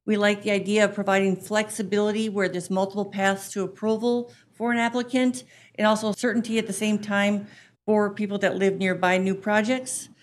told the council they are just getting started